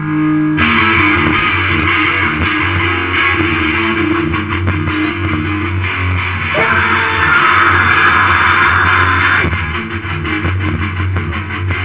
A nice sample of my crazy ass scream